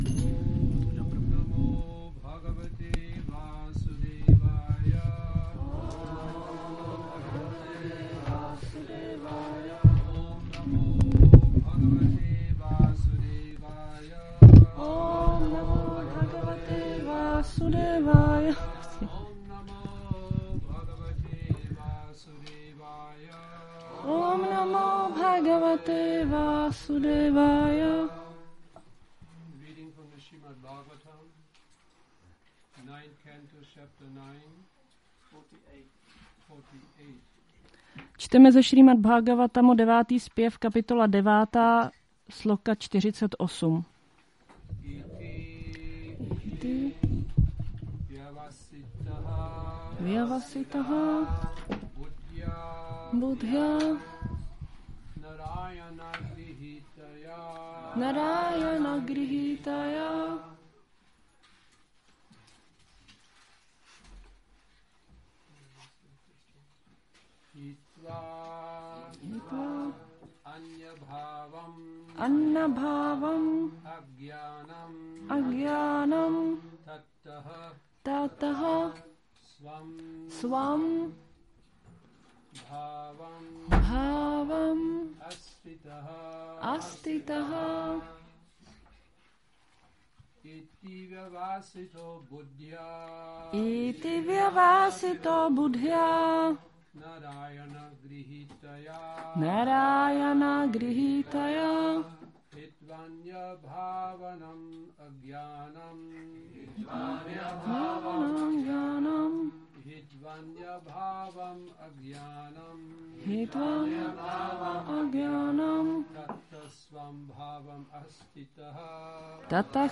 Přednáška SB-9.9.48 – Šrí Šrí Nitái Navadvípačandra mandir